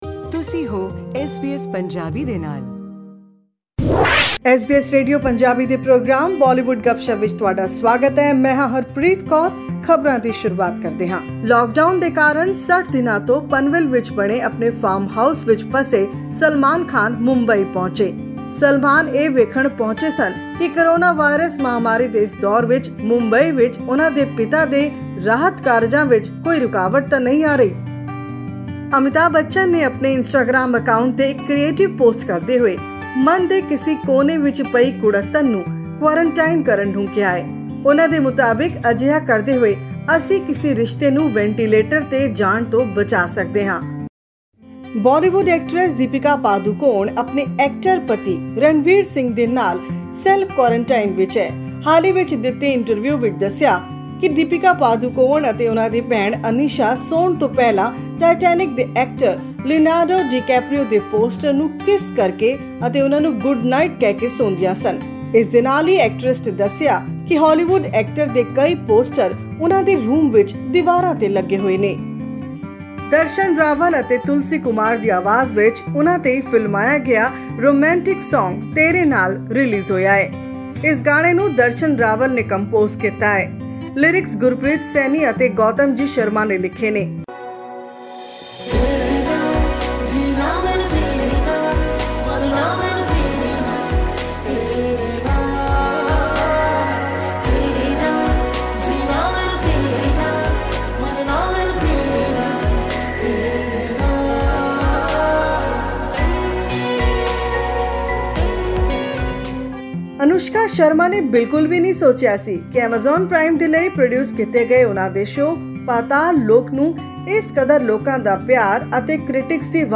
Hear the talk of the town in Bollywood this week, in this report.